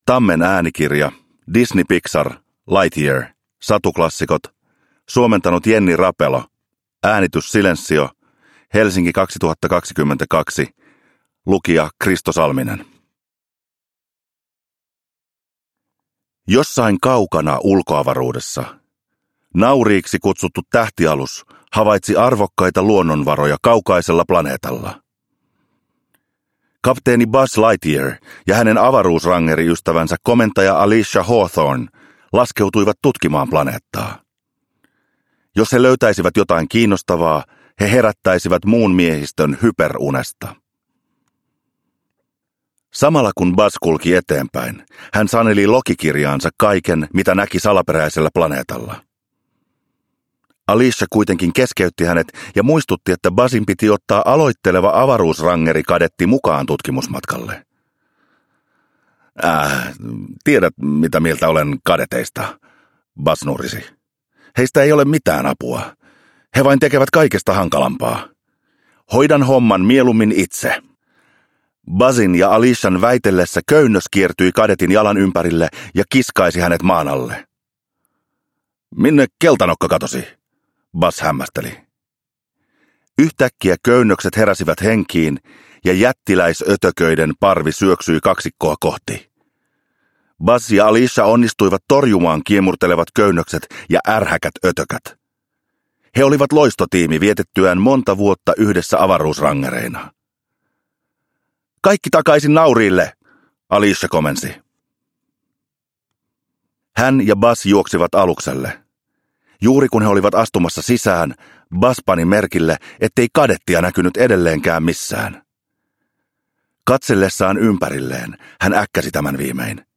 Disney Pixar. Lightyear. Satuklassikot – Ljudbok – Laddas ner
Uppläsare: Kristo Salminen